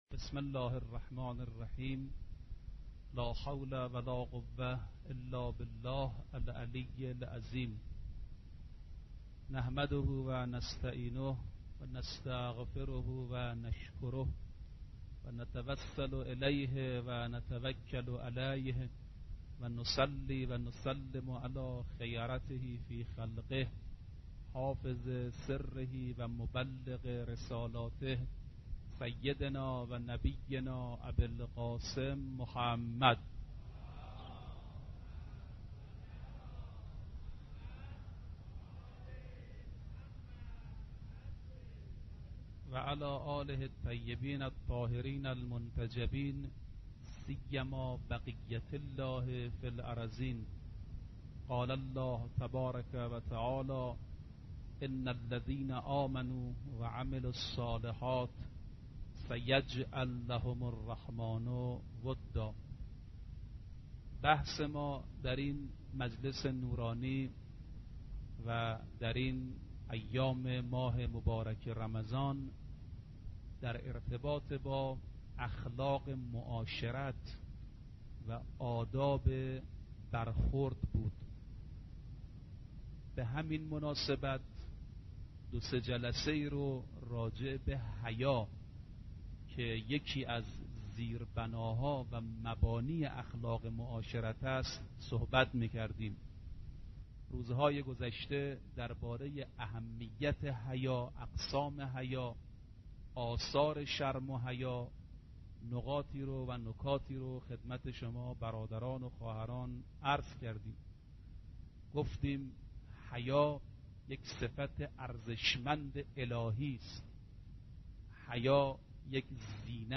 آرشیو ماه مبارک رمضان - سخنرانی - بخش پنجم